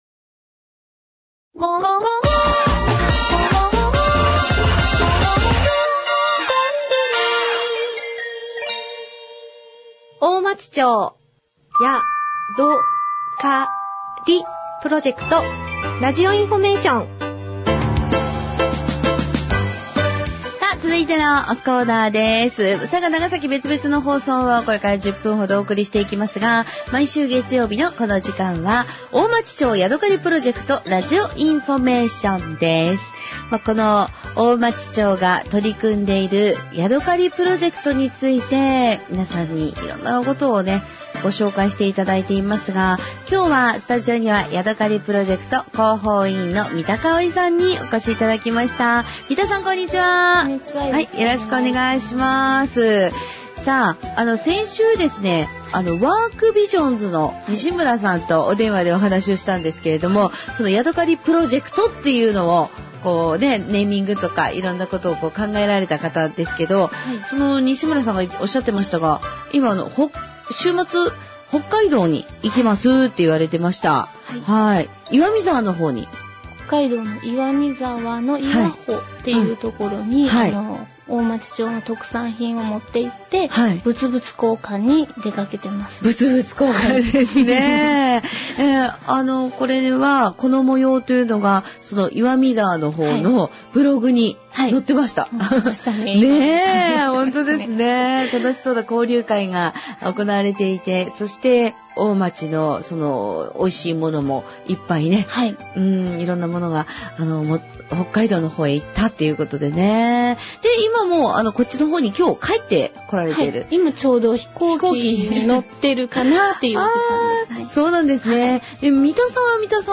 このページでは、平成27年1月26日放送から平成27年6月29日の期間、NBCラジオ佐賀で放送された「大町町やどかりプロジェクトラジオインフォメーション」の番組内容をご紹介します。